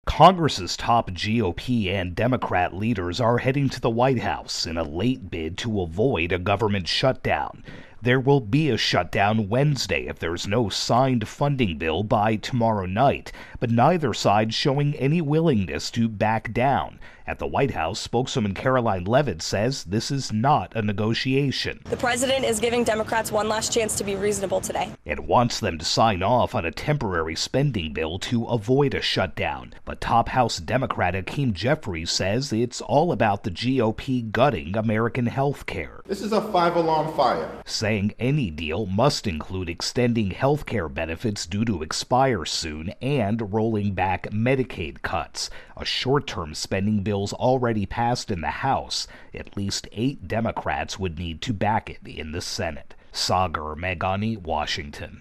AP Washington correspondent